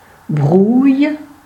The Broye (French: [bʁwa] ; Arpitan: Brouye[1] [bʁuj(ə)]
Frp-greverin-Brouye.ogg.mp3